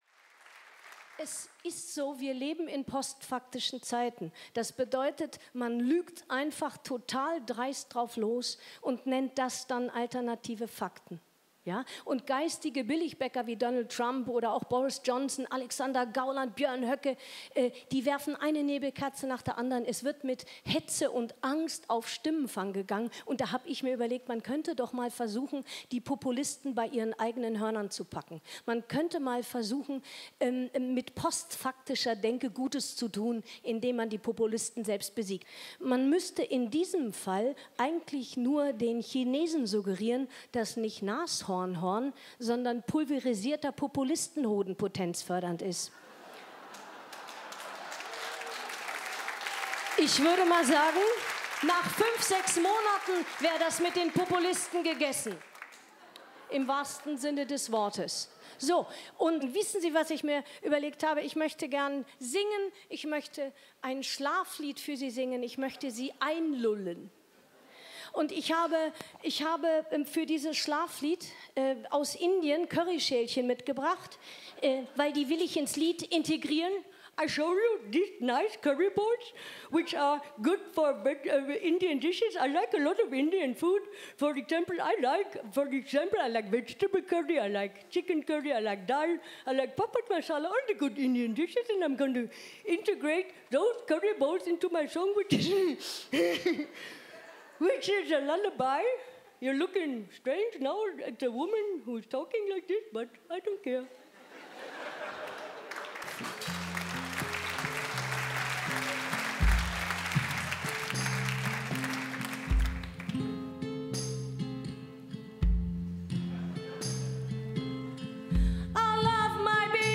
Nessi-Tausendschoen_30-Jahre-Zenit_Ausschnitt_3satFestival.mp3